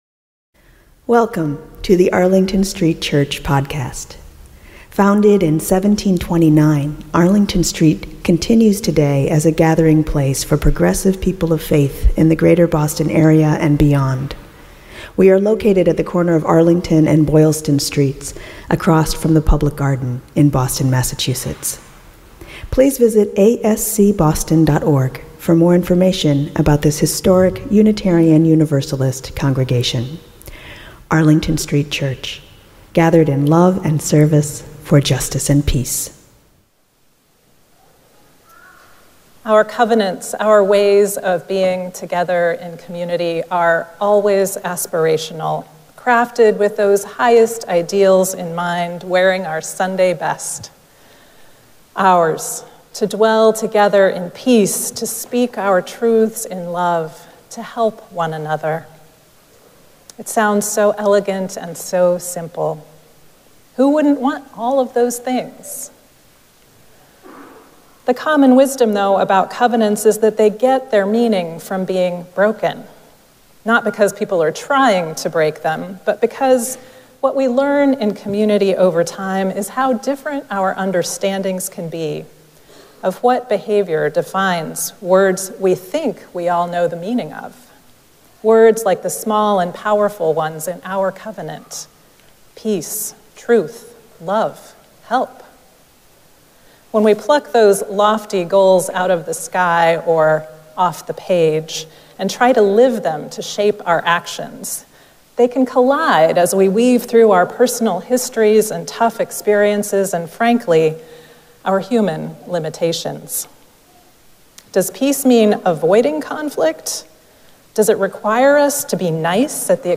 The sermon podcast from Arlington Street Church delivers our weekly sermon to listeners around the world.